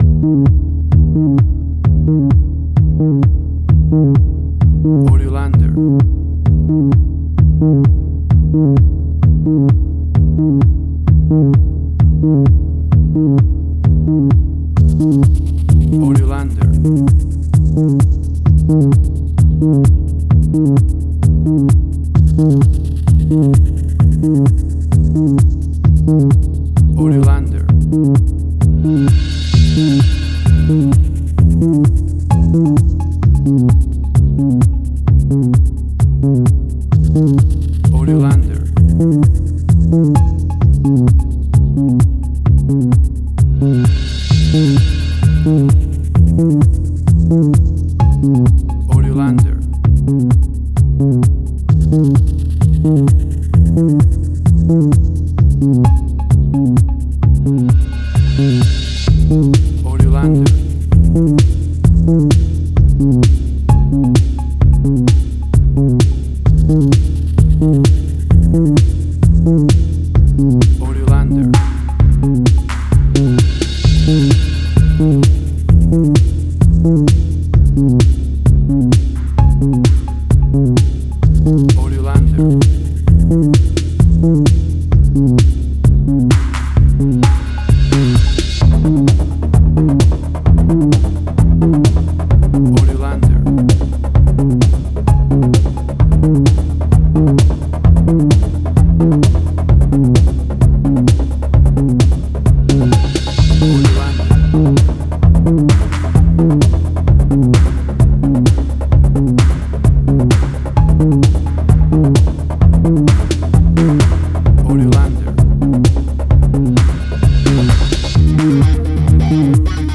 House.
Tempo (BPM): 130